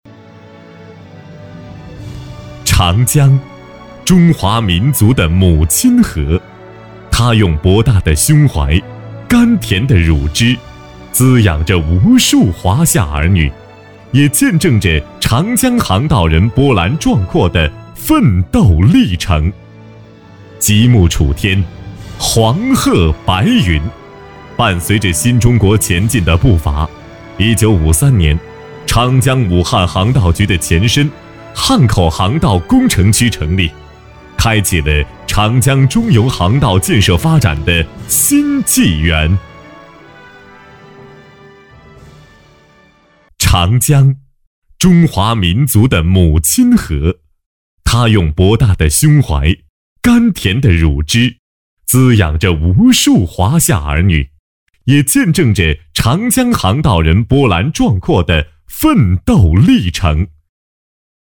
擅长：专题片 广告
特点：大气 品质
风格:磁性配音